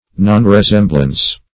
Search Result for " nonresemblance" : The Collaborative International Dictionary of English v.0.48: Nonresemblance \Non`re*sem"blance\, n. Want of resemblance; unlikeness; dissimilarity.